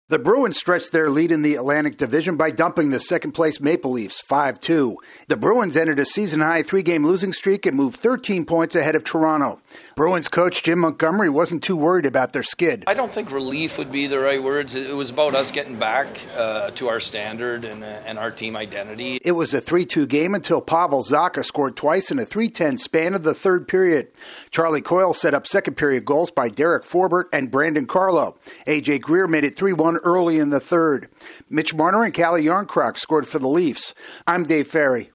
The Bruins get back to their winning ways. AP correspondent